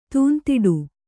♪ tūntiḍu